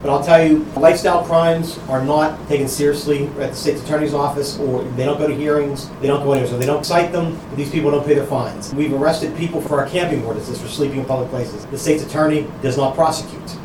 Shopkeepers are dealing with individuals loitering, leaving trash and dropping cigarette butts – in an area deemed to be no smoking.  At Thursday’s Downtown Development Commission meeting, City Administrator Jeff Silka shared that citing individuals multiple times is useless if the courts do not follow up…